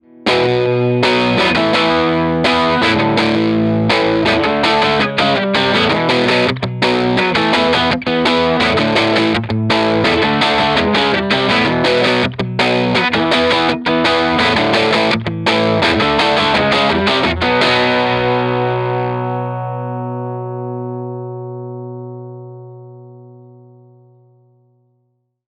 18 Watt v6 - EL84 Dirty Tone Tubby Alnico
Note: We recorded dirty 18W tones using both the EL84 and 6V6 output tubes.
In this one I prefer the clarity and brilliance of the TTA.
18W_DIRTY_EL84_ToneTubbyAlnico.mp3